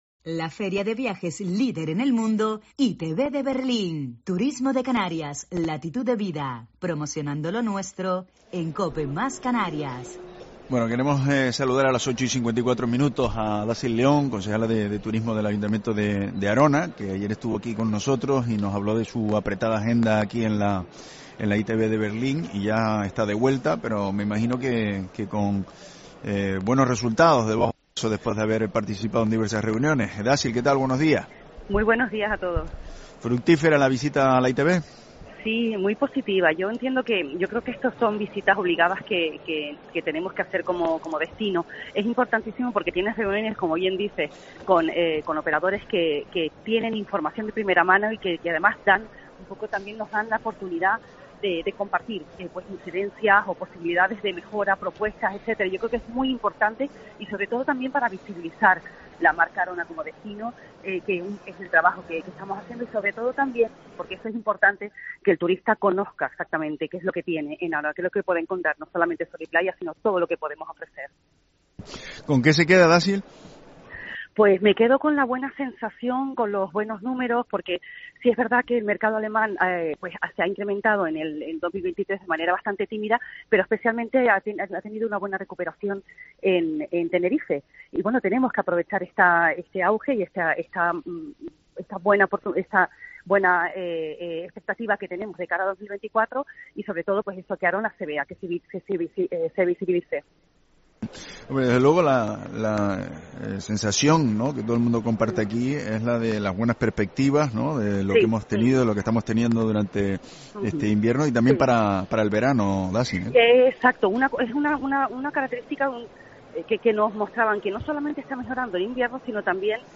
Dácil León, concejala de Turismo Arona en ITB